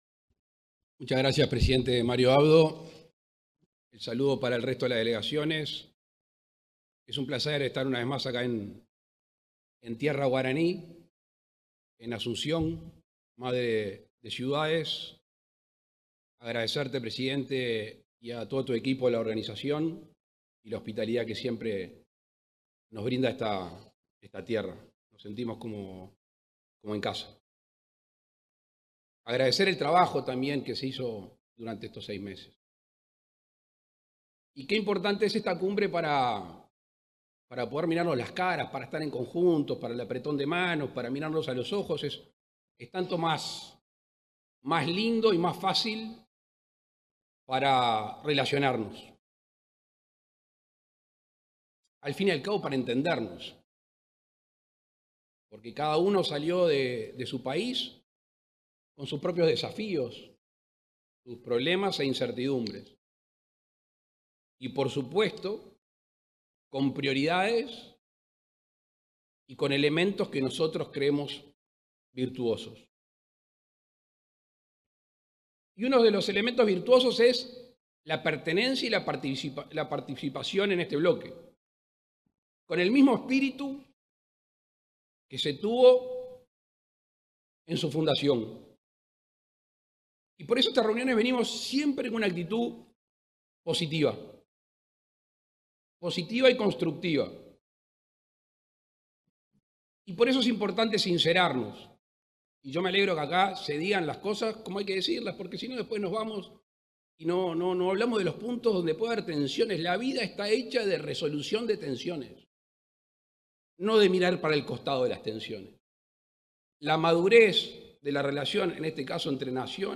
Intervención del presidente Luis Lacalle Pou en la Cumbre de Jefes de Estado del Mercosur, que se llevó a cabo este jueves 21 en Paraguay.